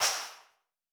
TC PERC 08.wav